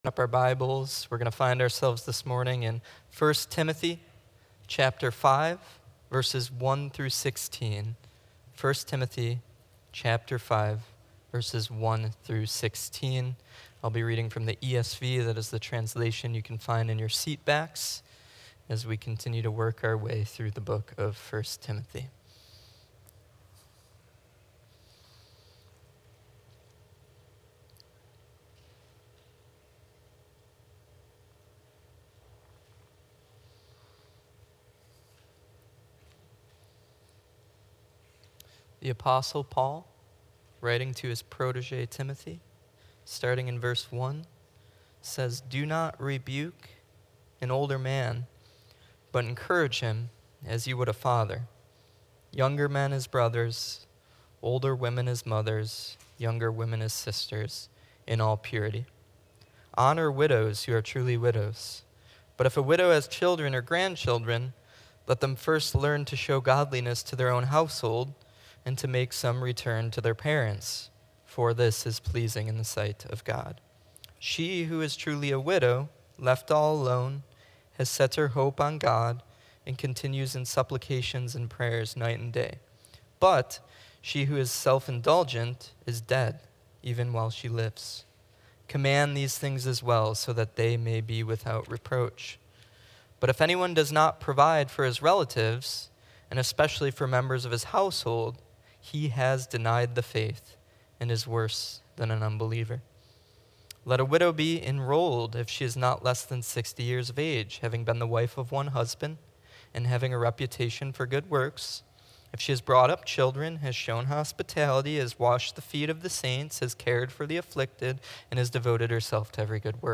A sermon from 1 Timothy 5:1–16 exploring how the church is called to function as the household of God